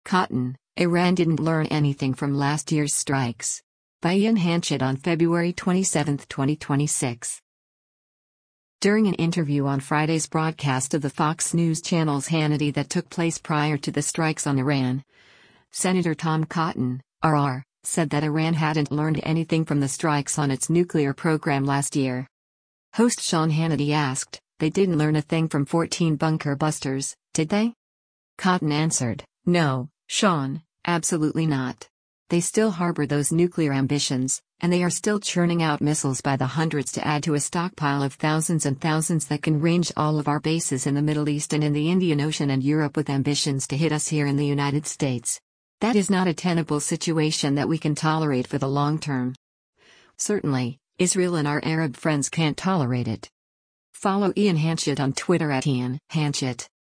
During an interview on Friday’s broadcast of the Fox News Channel’s “Hannity” that took place prior to the strikes on Iran, Sen. Tom Cotton (R-AR) said that Iran hadn’t learned anything from the strikes on its nuclear program last year.